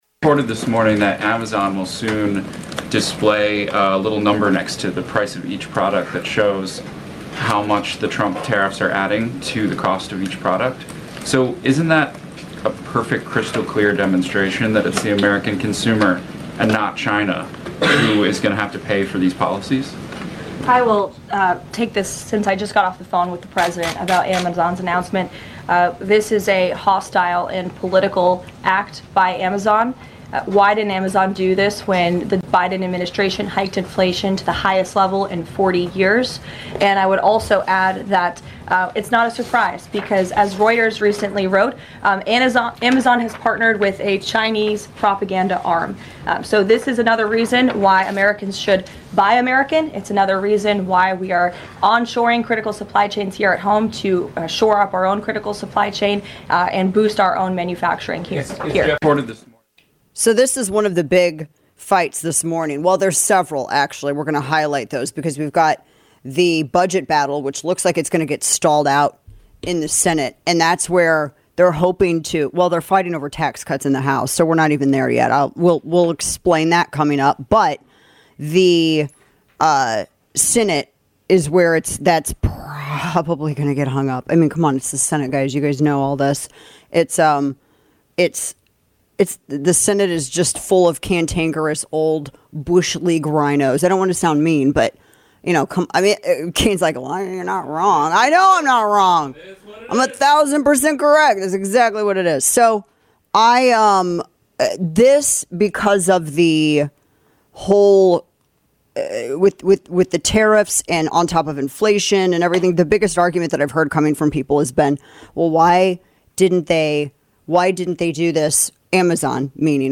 Florida Gov. Ron DeSantis joins us to discuss deportations, Operation Tidal Wave, Hope Florida, and his relationship with Tucker Carlson.